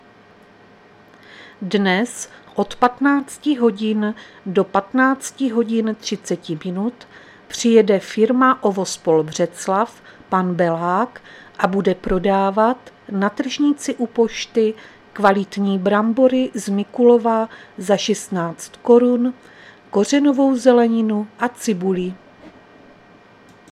Záznam hlášení místního rozhlasu 9.8.2023